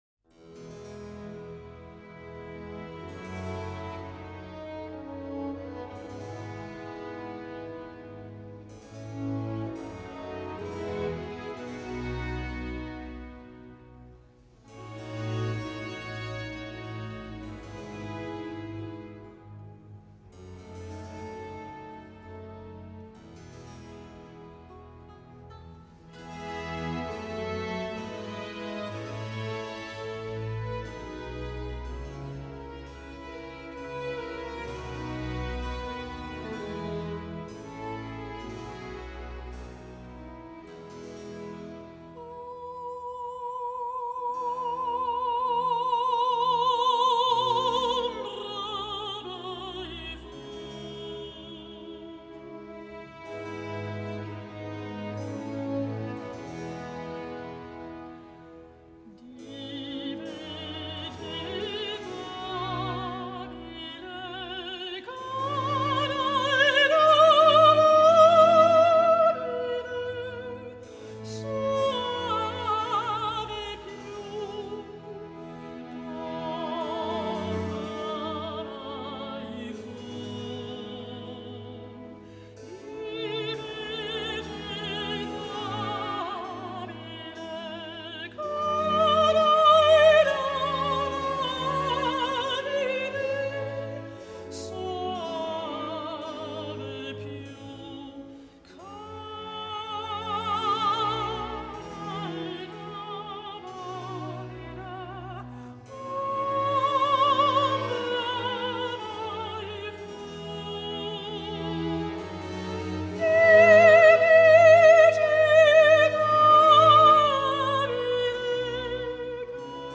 Нежная классика